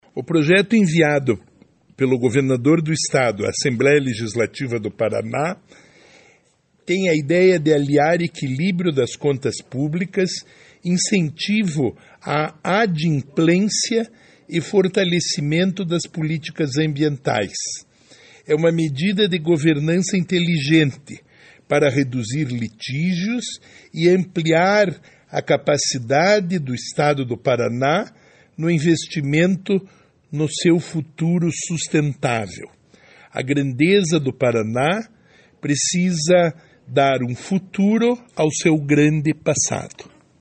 Sonora do secretário Estadual do Desenvolvimento Sustentável, Rafael Greca, sobre o Programa Regulariza Paraná